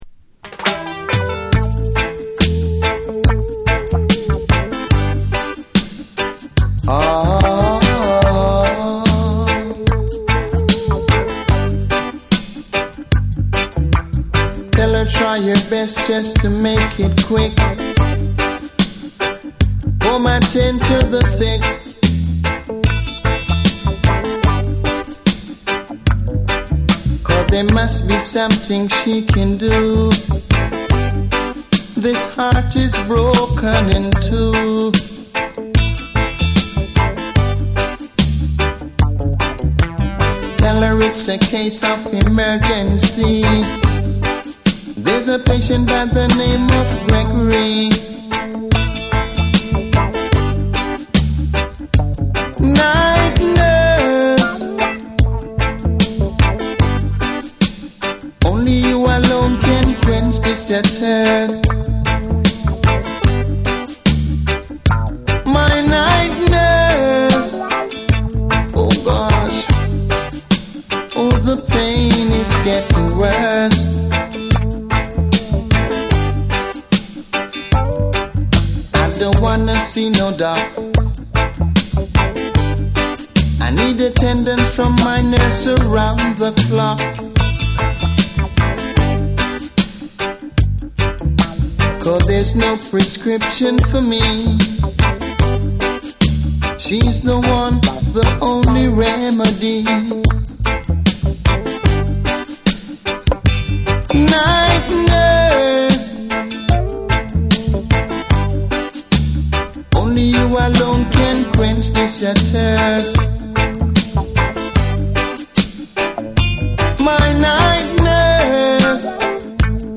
ロック、ダブ、ポストパンク、スカ、ヒップホップなどを横断したセレクションで、ダークでスモーキーな世界観を構築。
BREAKBEATS / NEW WAVE & ROCK / REGGAE & DUB